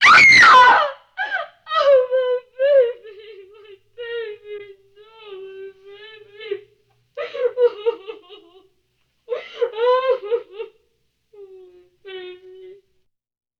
女声尖叫哭泣声音效免费音频素材下载